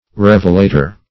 revelator - definition of revelator - synonyms, pronunciation, spelling from Free Dictionary Search Result for " revelator" : The Collaborative International Dictionary of English v.0.48: Revelator \Rev"e*la`tor\, n. [L.] One who makes a revelation; a revealer.
revelator.mp3